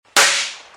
Sound Effects
Loud Shot Firework Pop